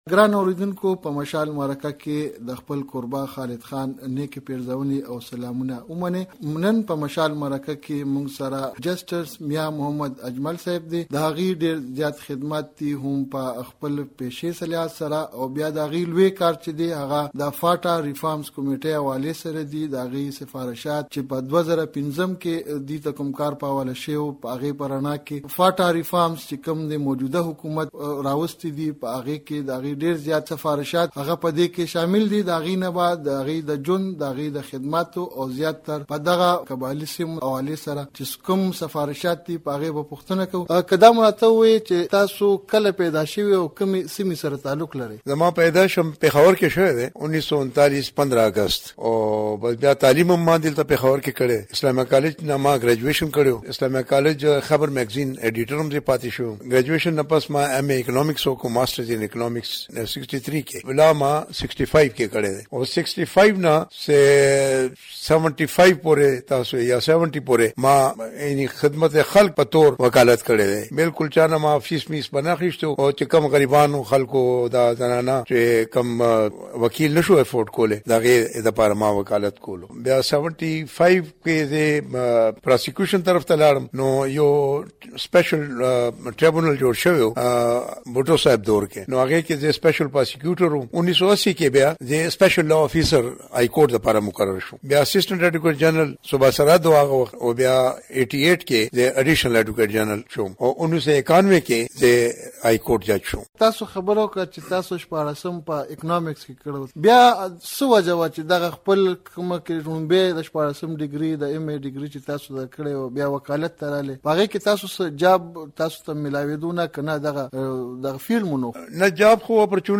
پر ایف سي آر له پخواني قاضي میا اجمل سره مرکه